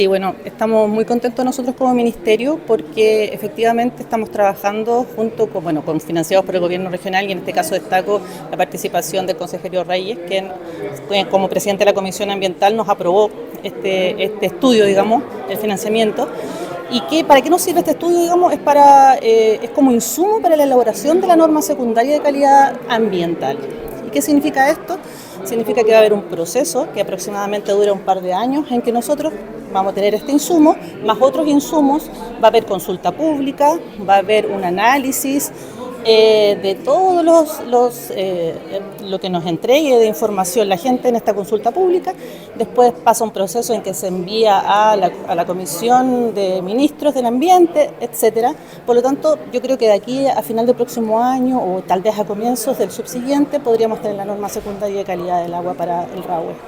Este estudio fue financiado por el Gobierno Regional de Los Lagos, lo que permitirá obtener los datos precisos y necesarios para abordar la contaminación en los cauces del río, además para la construcción de la Norma Secundaria, también se considerará la participación ciudadana como señaló la Seremi del Medioambiente Alejandra de la Fuente.